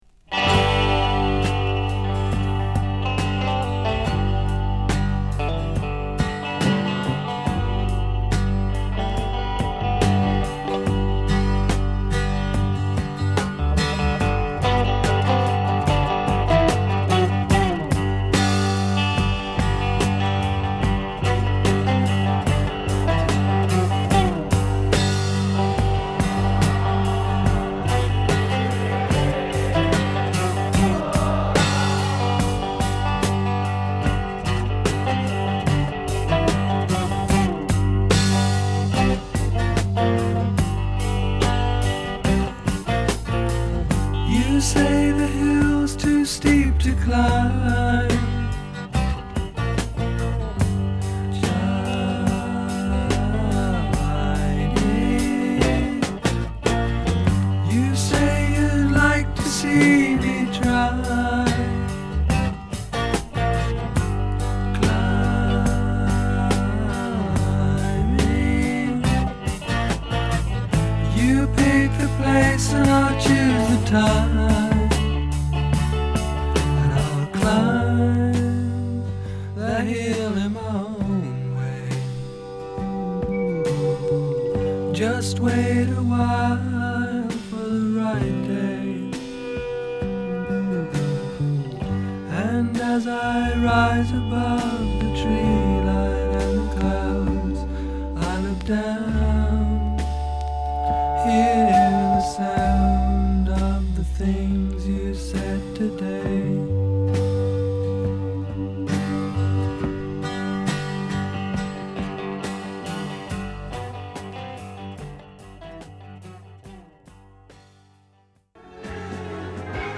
独特の幻想的／倦怠感を帯びた曲で、中盤からのブレイク感あるヘビーなサウンド等はサンプリング／ブレイク好きにもオススメ。